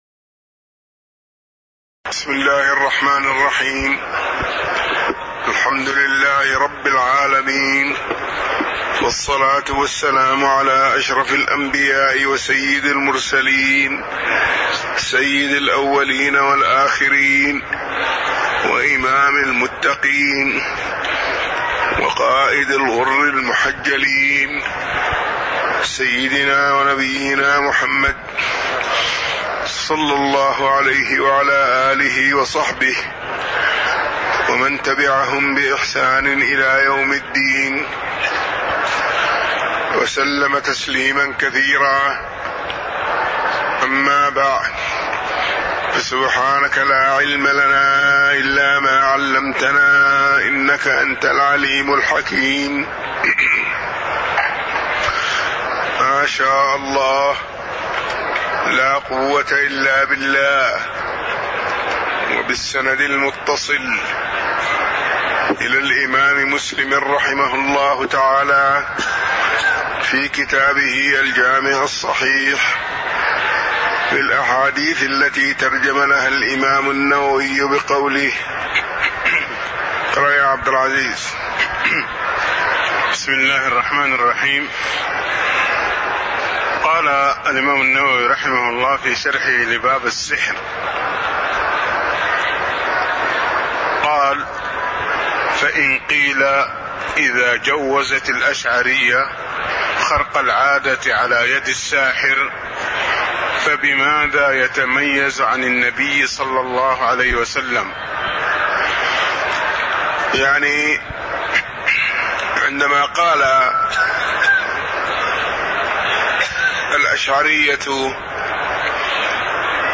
تاريخ النشر ٢٨ محرم ١٤٣٧ هـ المكان: المسجد النبوي الشيخ